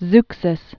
(zksĭs) Fifth century BC.